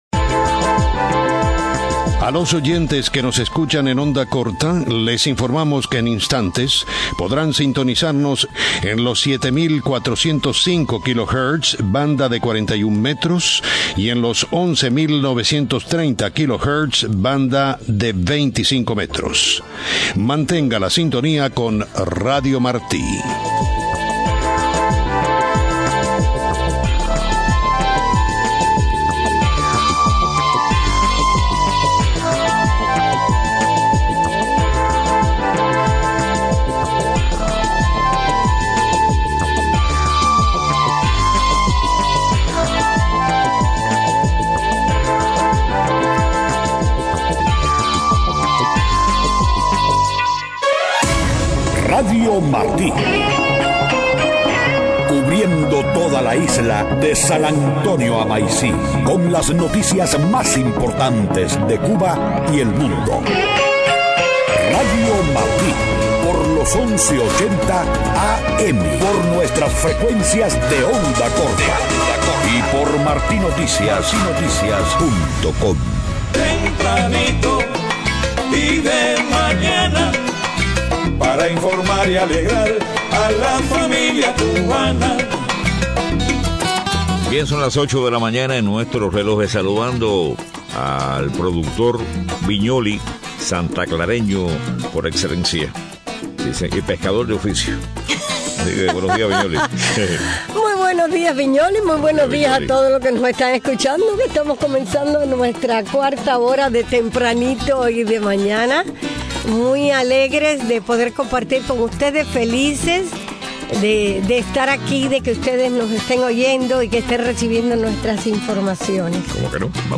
8:00 a.m. Noticias: Exiliados de Miami piden reunión paralela a la cumbre de la CELAC. Liberan en Cuba a muchos de los opositores arrestados en la cumbre de la CELAC.